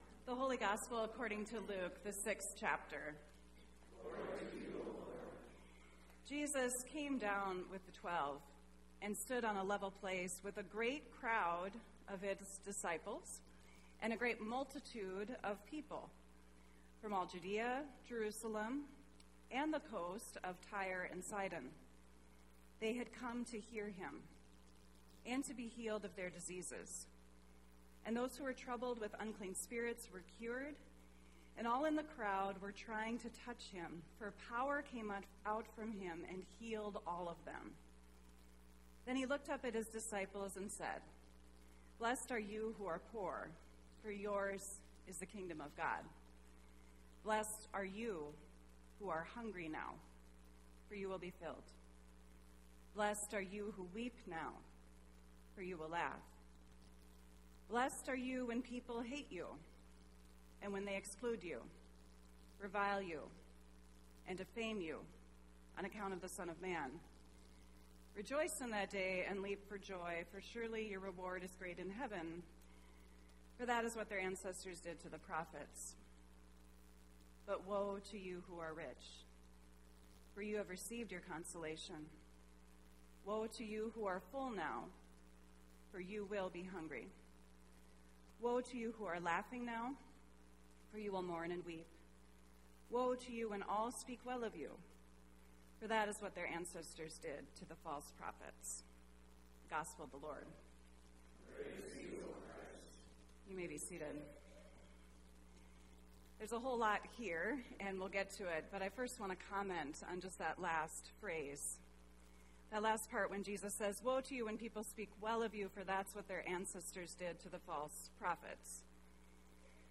Weekly Sermons from Calvary Lutheran Church Perham, Minnesota.